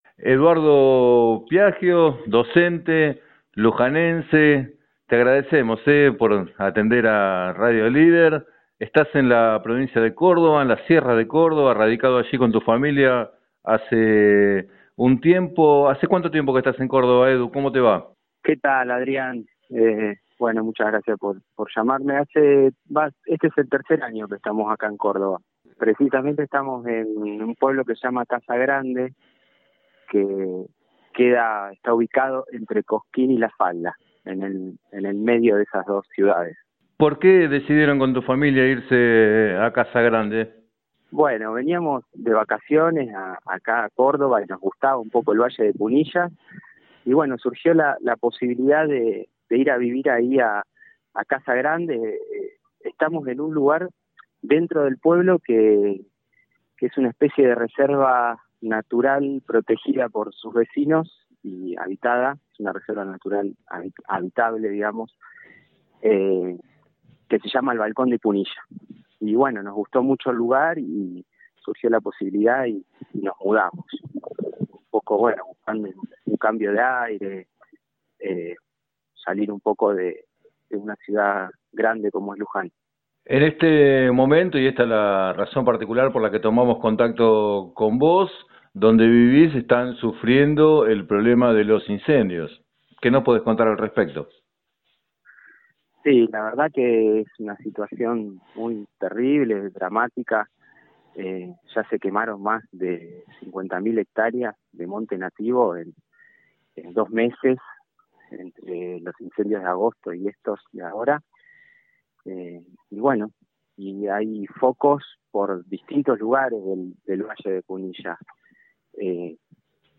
Entrevistado en el programa Planeta Terri de Radio Líder 97.7